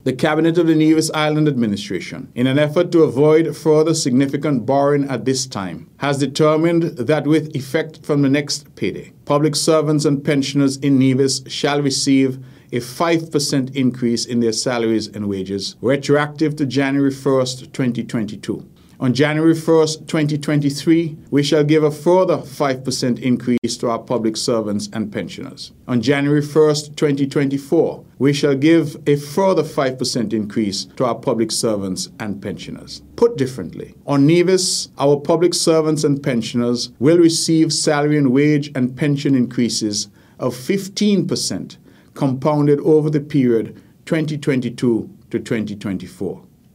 In an address on Monday night, the Hon. Mark Brantley, gave this information about the change.